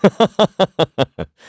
Clause_ice-Vox_Happy2_kr.wav